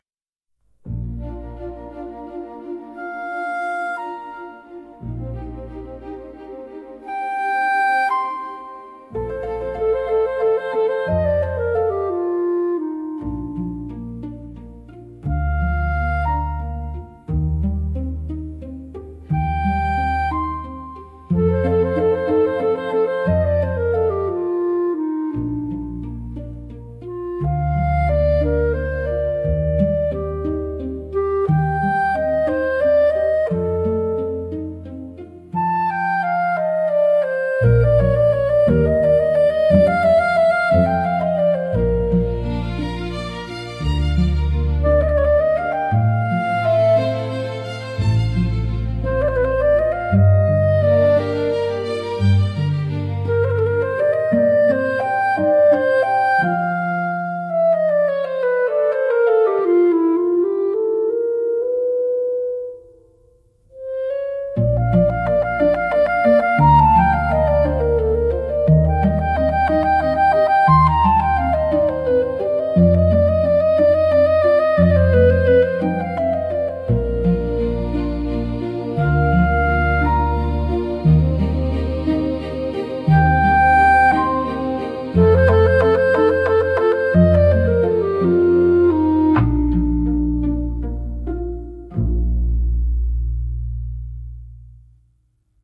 静かで清々しい空気感を演出しつつ、心に明るい希望や期待を芽生えさせる効果があります。